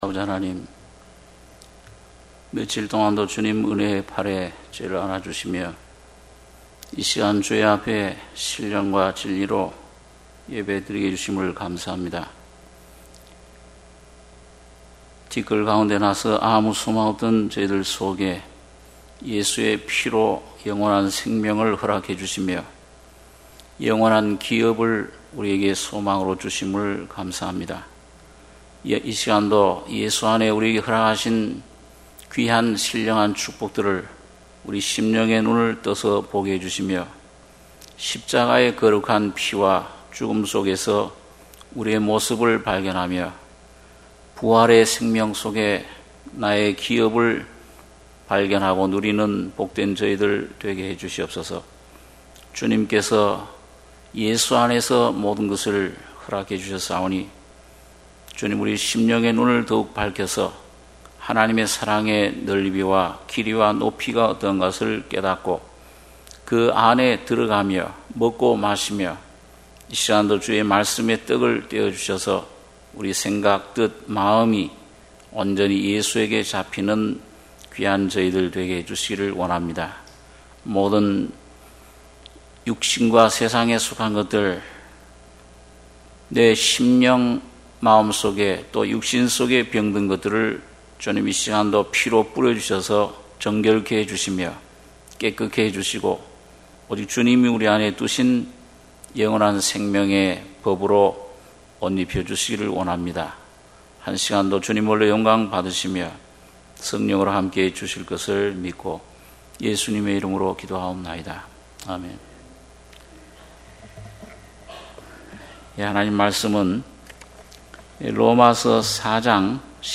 수요예배 - 로마서 4장 16-25절